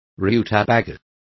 Also find out how nabas is pronounced correctly.